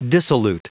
Prononciation du mot dissolute en anglais (fichier audio)
Prononciation du mot : dissolute